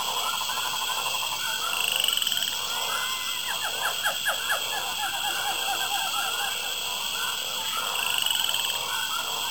Rainforest Ambience 3